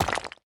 rock_destroyed_01.ogg